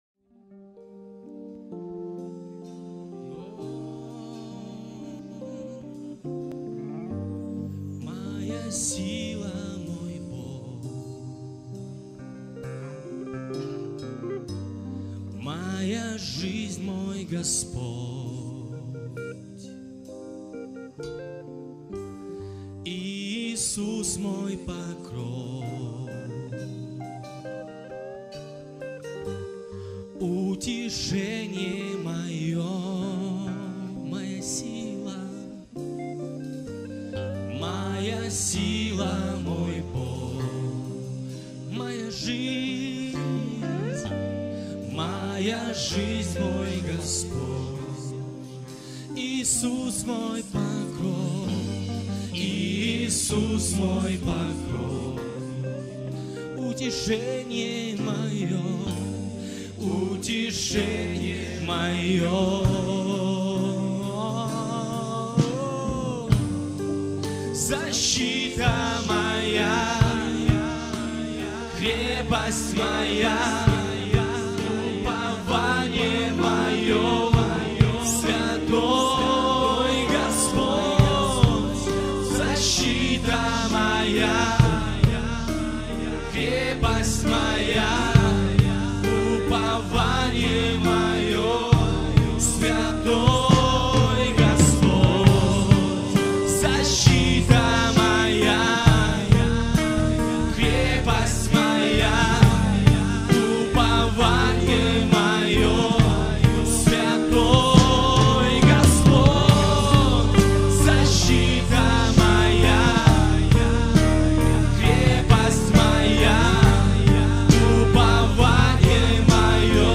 песня
44 просмотра 81 прослушиваний 3 скачивания BPM: 120